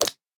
Minecraft Version Minecraft Version 25w18a Latest Release | Latest Snapshot 25w18a / assets / minecraft / sounds / mob / frog / long_jump4.ogg Compare With Compare With Latest Release | Latest Snapshot
long_jump4.ogg